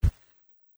土路上的脚步声2－偏低频－左声道－YS070525.mp3
通用动作/01人物/01移动状态/土路/土路上的脚步声2－偏低频－左声道－YS070525.mp3